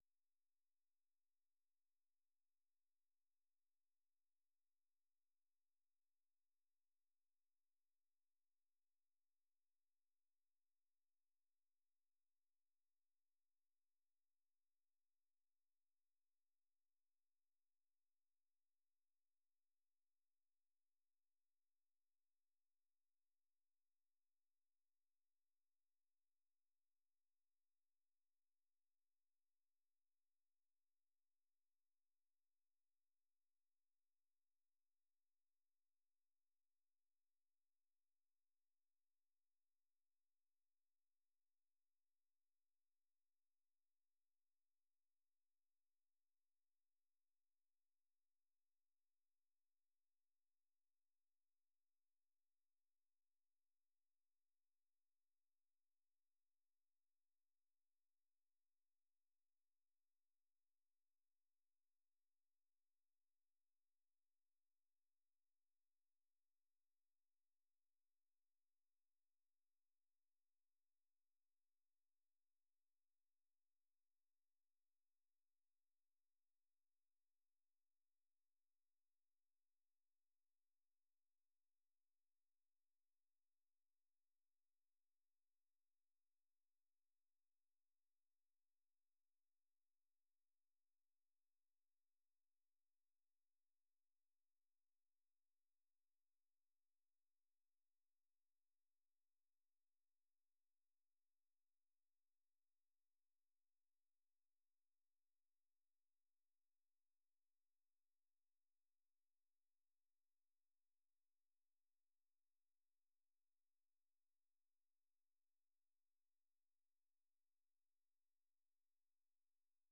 VOA 한국어 방송의 일요일 오후 프로그램 2부입니다. 한반도 시간 오후 9:00 부터 10:00 까지 방송됩니다.